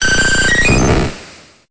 Cri d'Aquali dans Pokémon Épée et Bouclier.